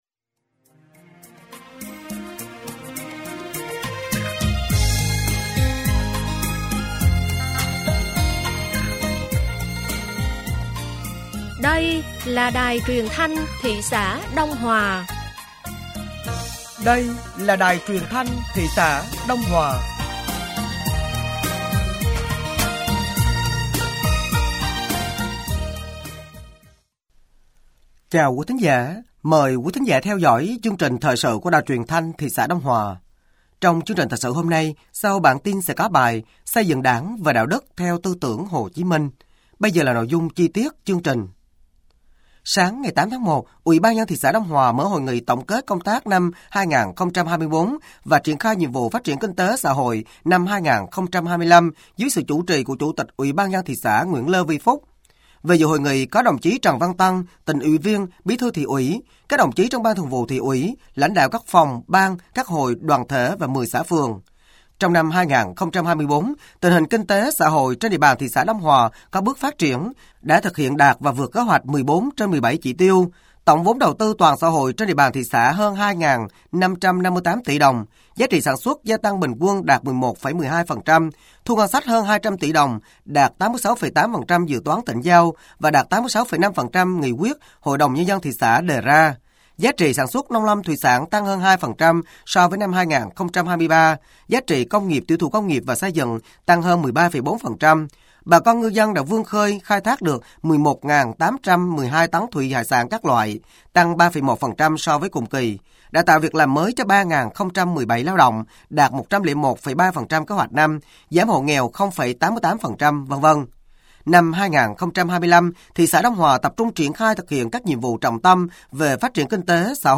Thời sự tối ngày 08 và sáng ngày 09 tháng 01 năm 2024